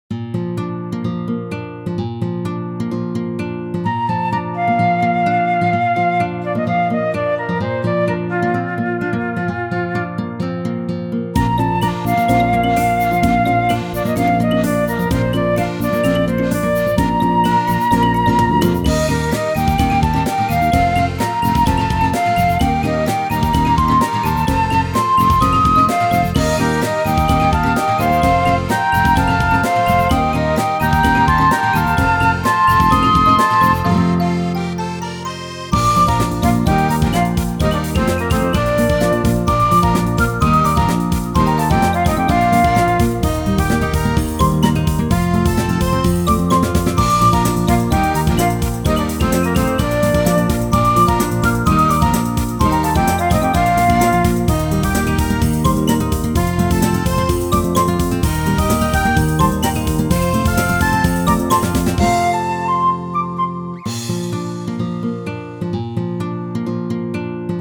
ogg(L) 街 にぎやか アップテンポ ポップ
軽快なギターとフルートが爽やかな曲。